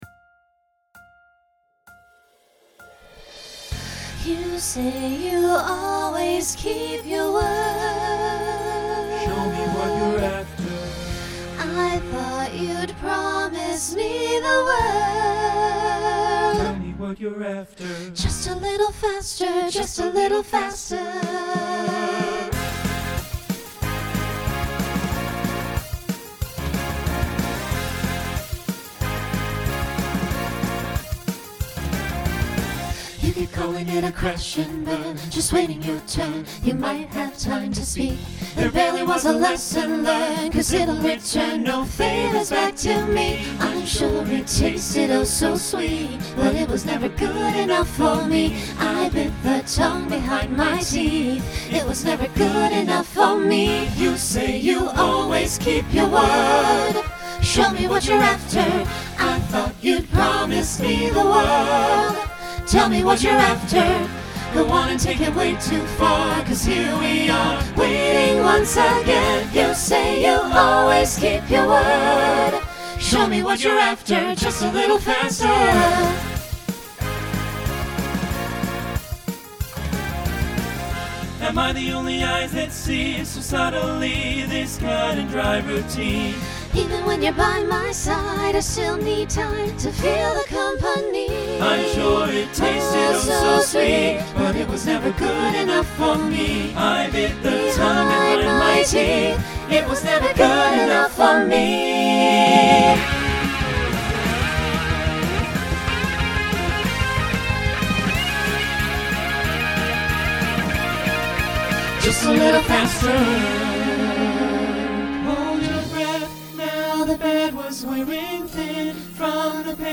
Rock Instrumental combo
Voicing SATB